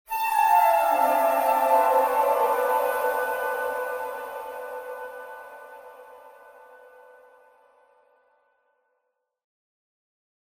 28 REV+DEL Spatial Reverb
Flute
Processed (Wet)
Echo-Chamber-25-Spatial Reverb Wet.mp3